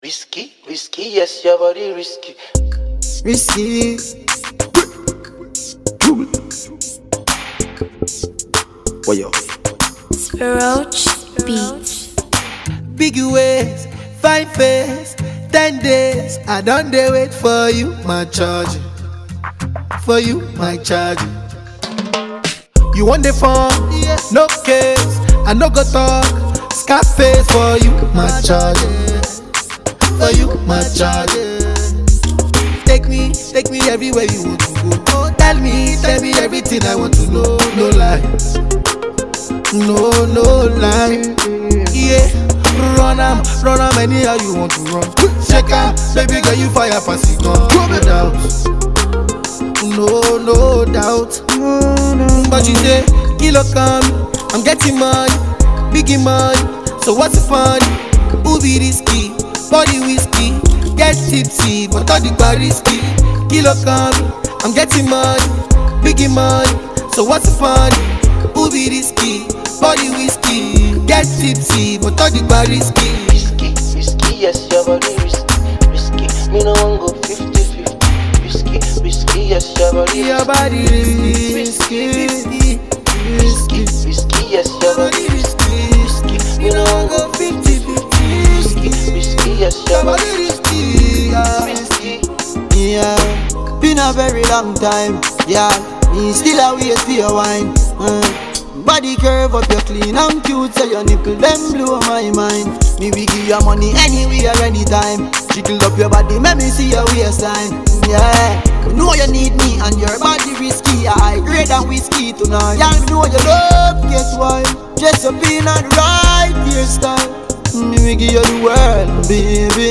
Jamaican patois crooner